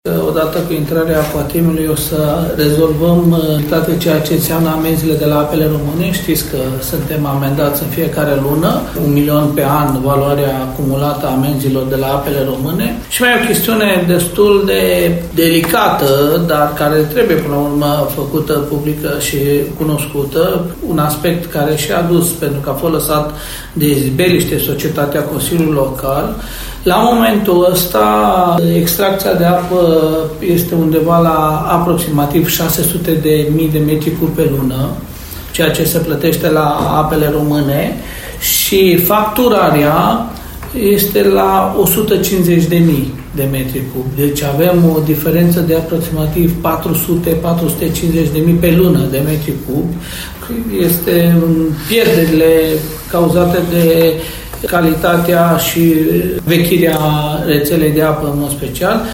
Amenzile imense pentru apele nepurate, pierderile uriașe de pe rețea și gradul de încasare redus reprezintă problemele cu care s-a confruntat Meridian, spune primarul Lugojului, Călin Dobra.